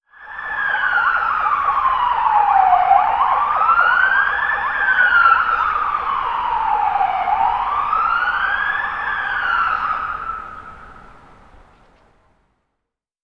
emergency-car-arrival.wav